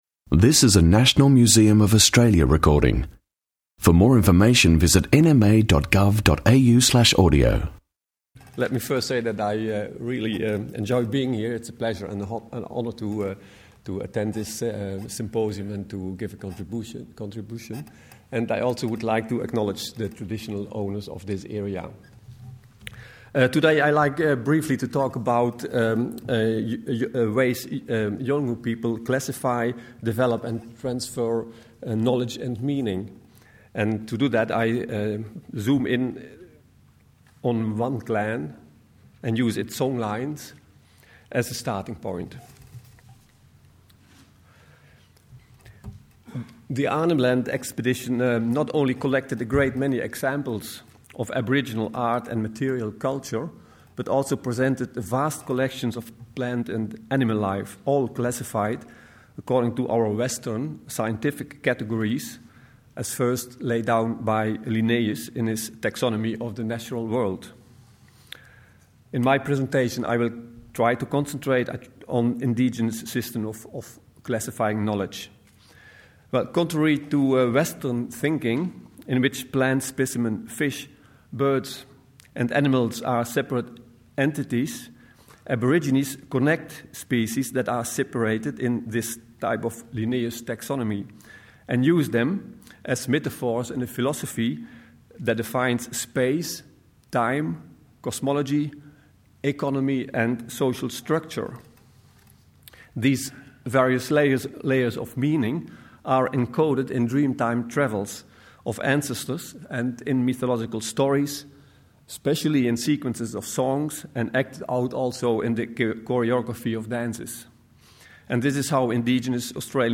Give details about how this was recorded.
Barks, Birds and Billabongs symposium 19 Nov 2009